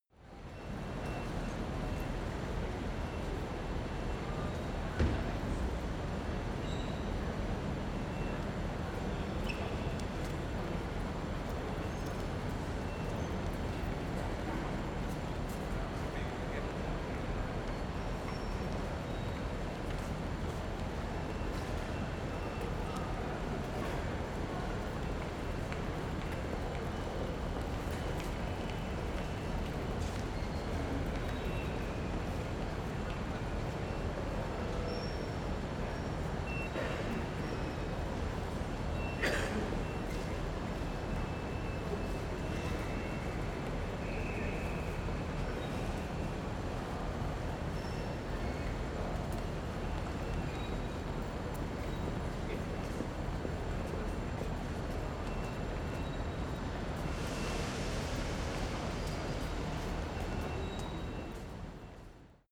Royalty free sounds: Railway station